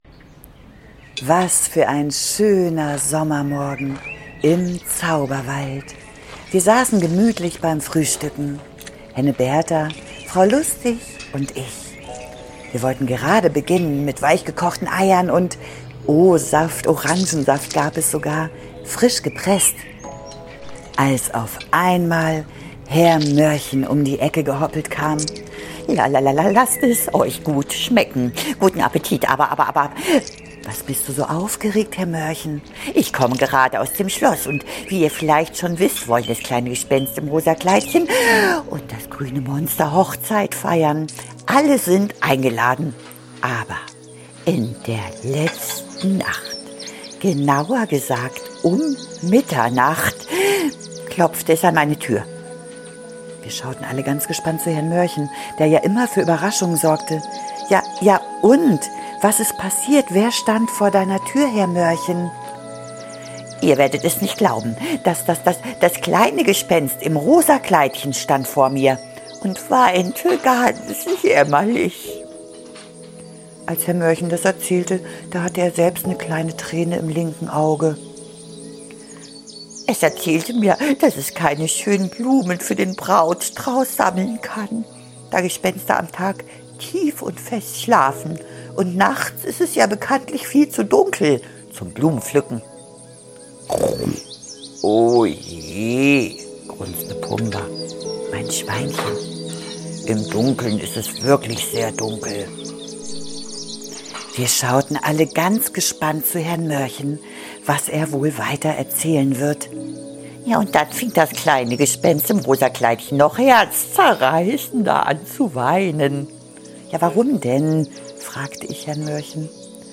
Klang und Magie- Ein Hörspiel zum Träumen und staunen . Ideal für Kinder und alle, die sich gerne verzaubern lassen .